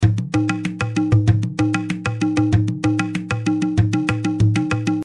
PW Tabla Loop 1
Tag: 96 bpm Ethnic Loops Tabla Loops 865.44 KB wav Key : Unknown